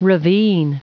Prononciation du mot ravine en anglais (fichier audio)
Prononciation du mot : ravine
ravine.wav